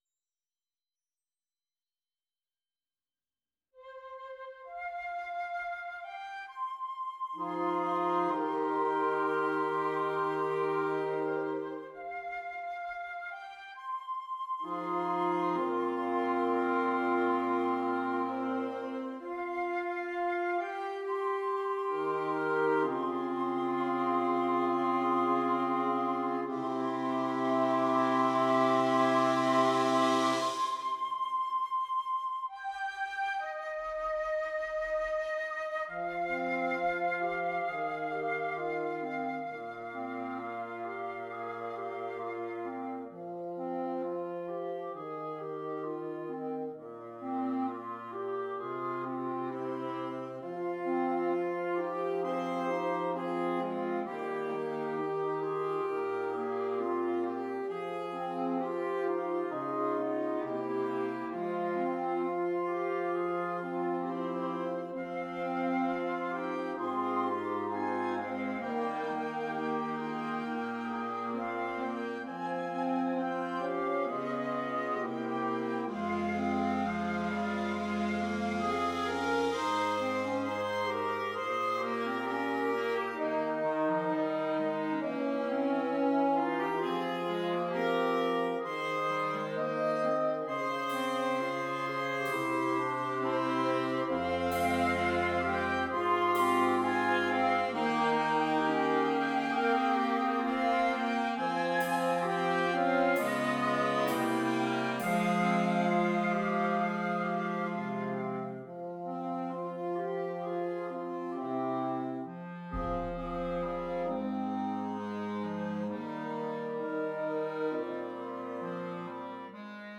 Interchangeable Woodwind Ensemble
Canadian Folk Song
PART 1 - Flute, Clarinet, Alto Saxophone
PART 6 - Bass Clarinet, Bassoon, Baritone Saxophone